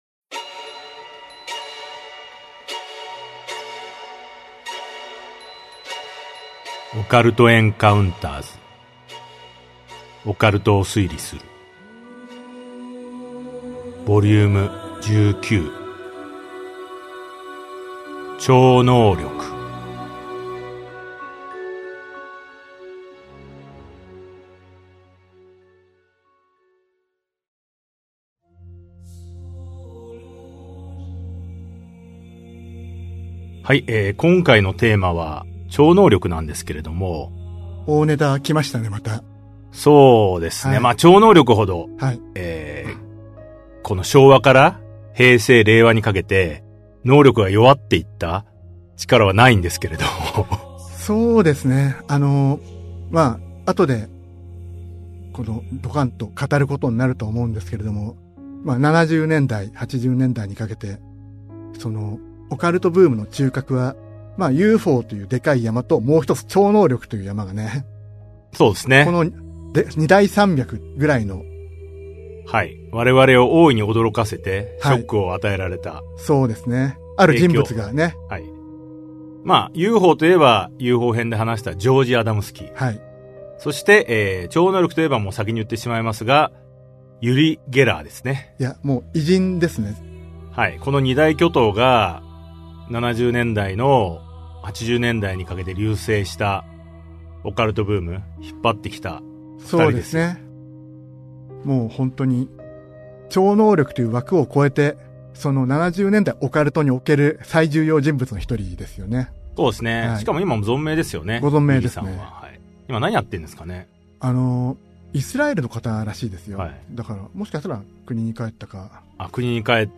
[オーディオブック] オカルト・エンカウンターズ オカルトを推理する Vol.19 超能力編